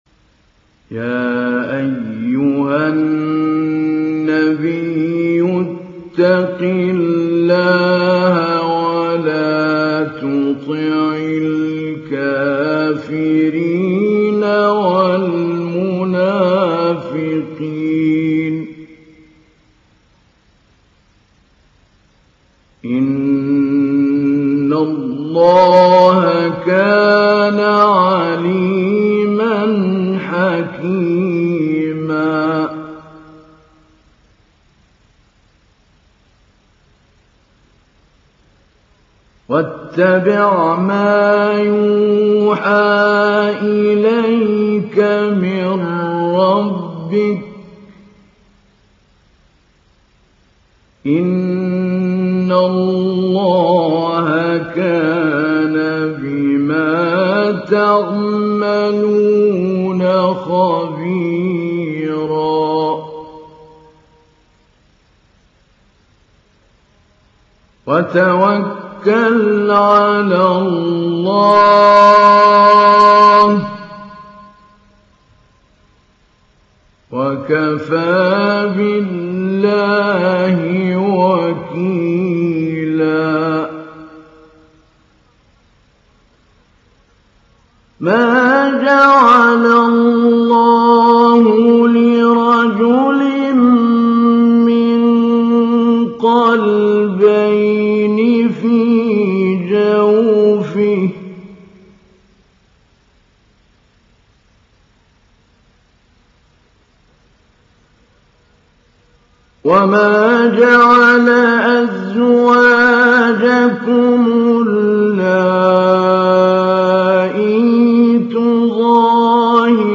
Ahzab Suresi İndir mp3 Mahmoud Ali Albanna Mujawwad Riwayat Hafs an Asim, Kurani indirin ve mp3 tam doğrudan bağlantılar dinle
İndir Ahzab Suresi Mahmoud Ali Albanna Mujawwad